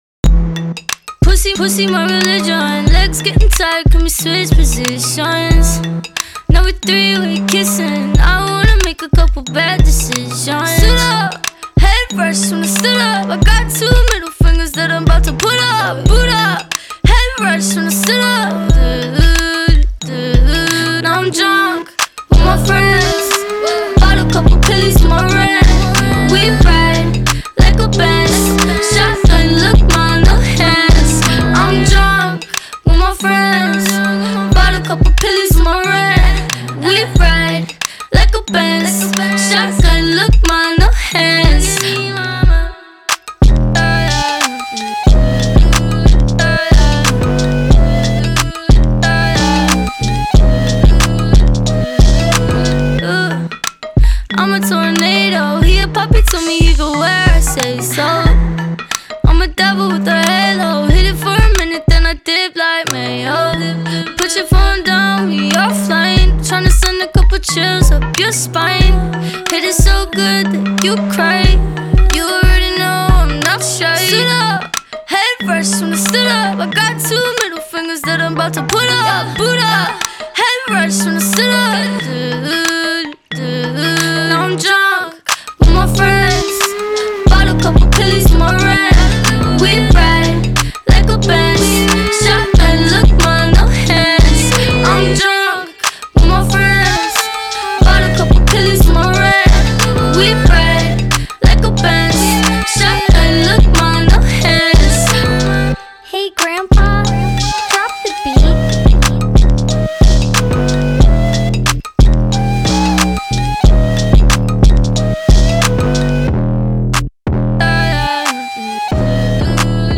энергичная поп-панк песня